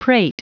Prononciation du mot prate en anglais (fichier audio)
Prononciation du mot : prate